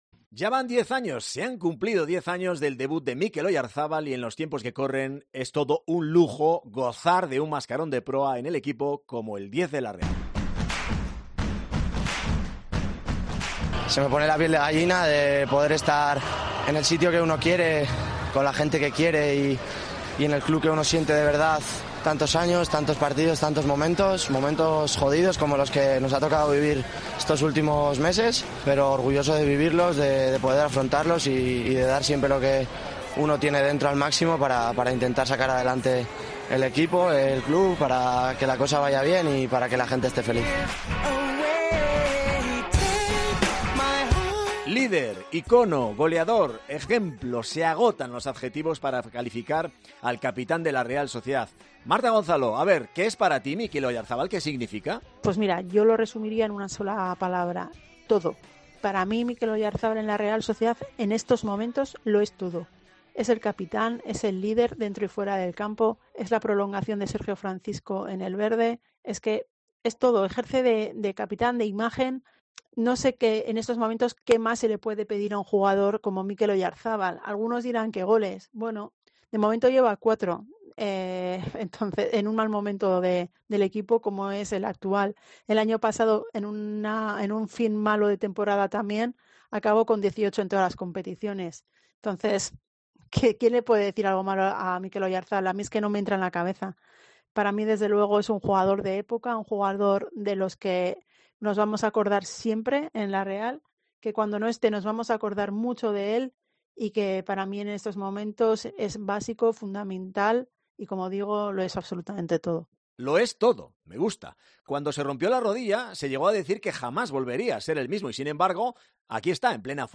En la tertulia